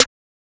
twatsnare1.wav